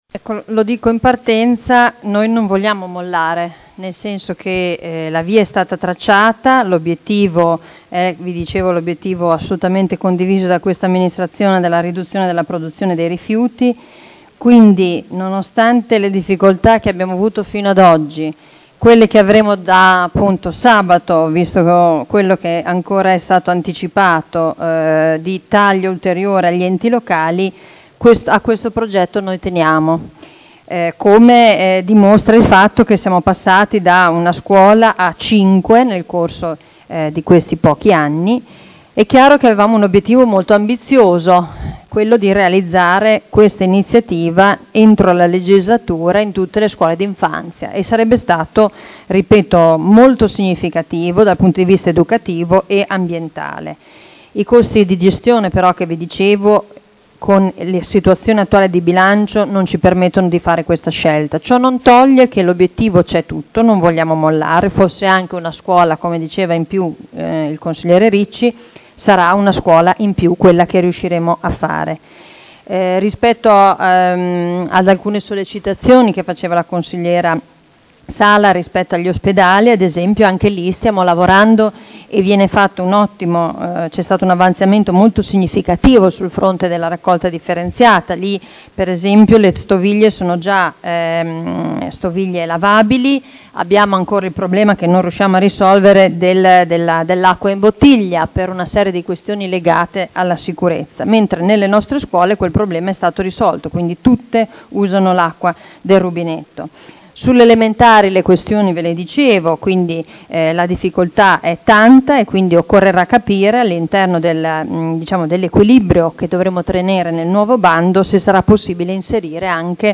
Seduta del 17/07/2011. Conclusioni su dibattito interrogazione dei consiglieri Sala e Trande (P.D.) avente per oggetto: “Sostenibilità nelle mense scolastiche” – Primo firmatario consigliera Sala (presentata l’11 aprile 2011 – in trattazione il 14.7.2011) e interrogazione del consigliere Ricci (Sinistra per Modena) avente per oggetto: “Interventi di sostenibilità nelle mense scolastiche” (presentata il 19 aprile 2011 – in trattazione il 14.7.2011)